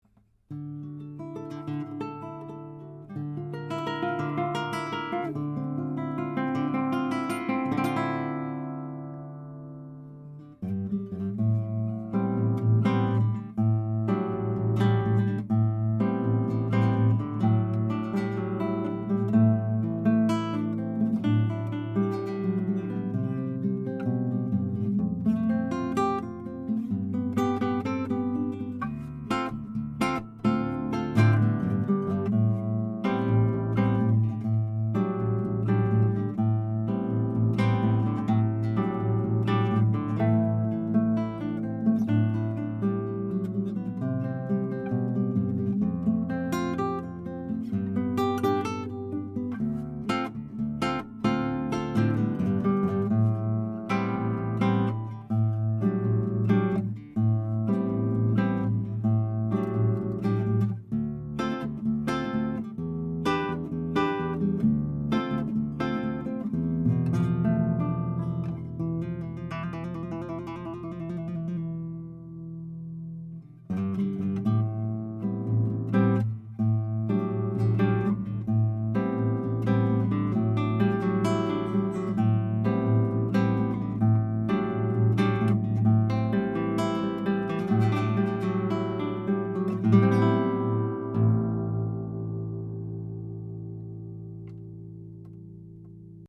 Flickingers's have a great vintage character and tone that imparts a creaminess and heft to your tracks.
Tracked using a SAMAR Audio modded AKG C1000S (left channel) and a vintage AKG C451/CK1 (right channel) through a Metric Halo ULN-8 interface:
NYLON STRING HARP GUITAR